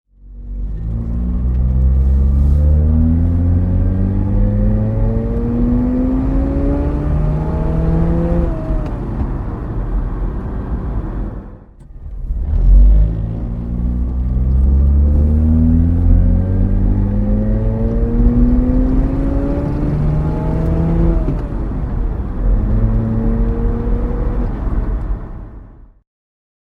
Citroën GS Birotor (1974) - Innengeräusch
Citroen_GS_Birotor_1974_-_innen_v2.mp3